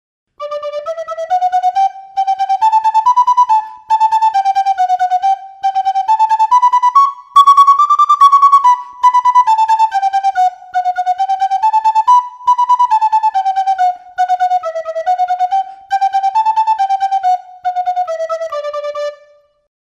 Picado dobre
Pito Galego